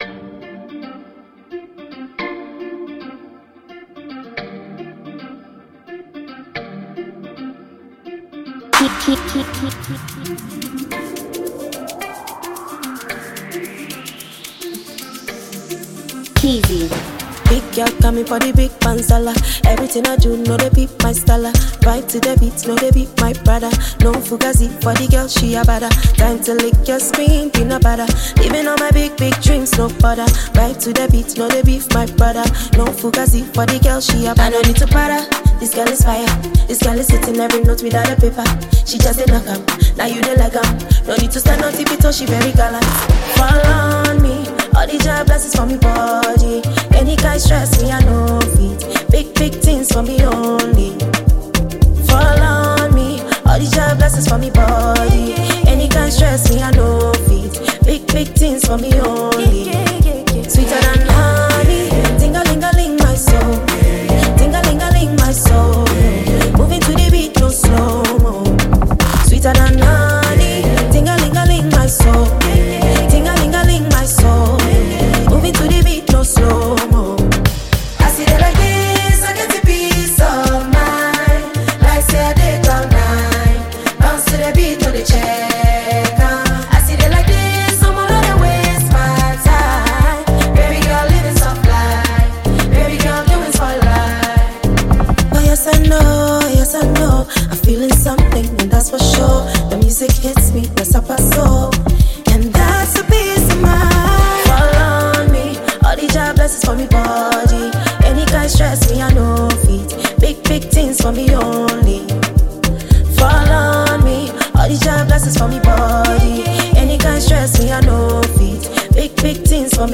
Ghanaian female musician and songwriter